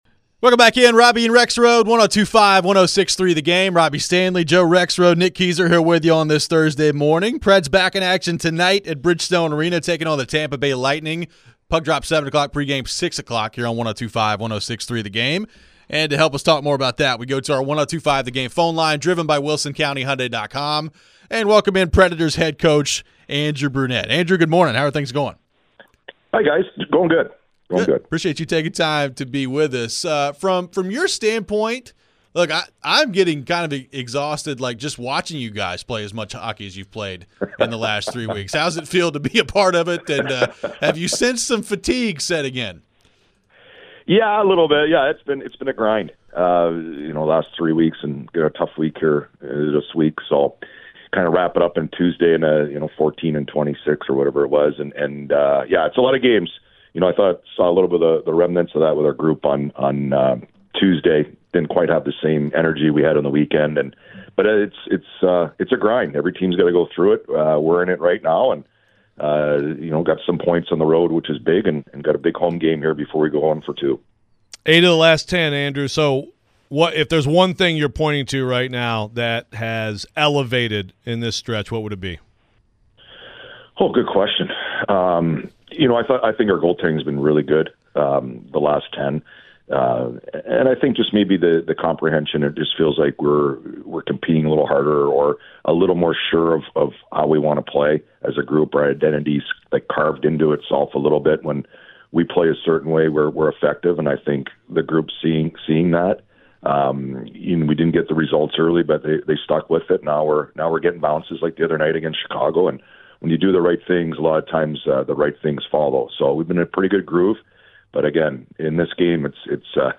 Andrew Brunette Interview (12-7-23)